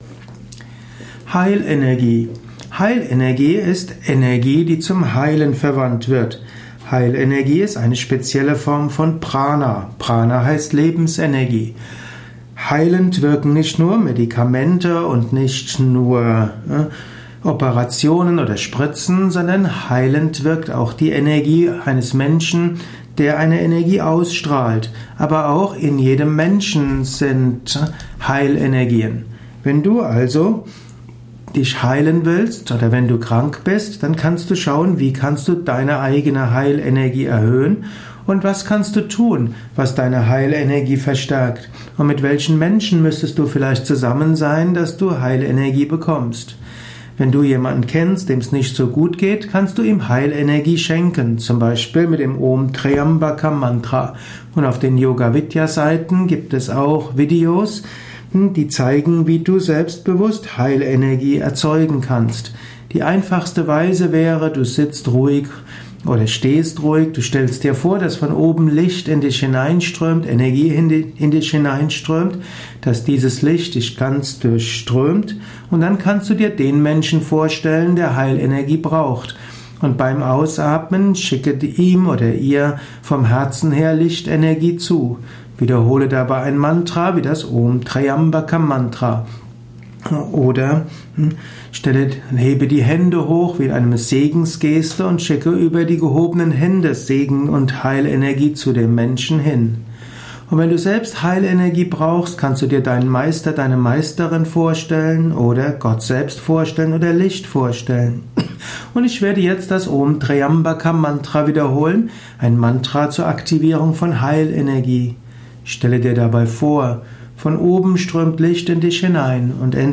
Heilenergie - Vortragsvideo